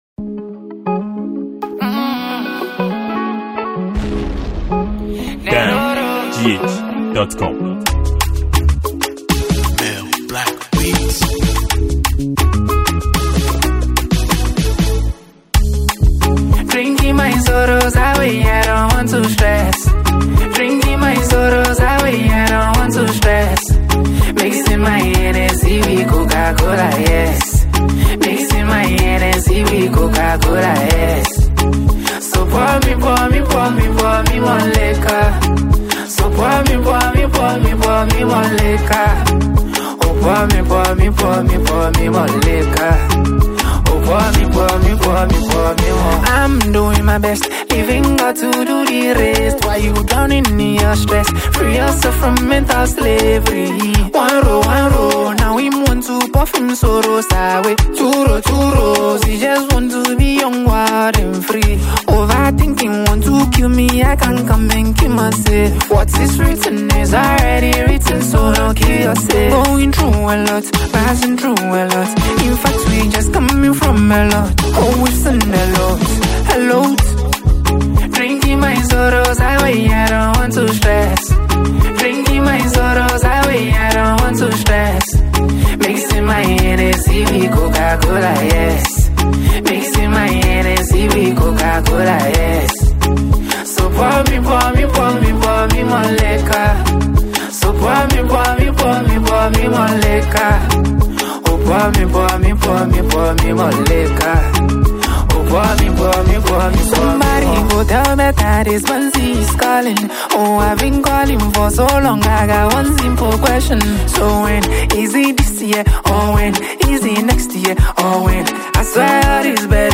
Ghana Music
a singer from Ghana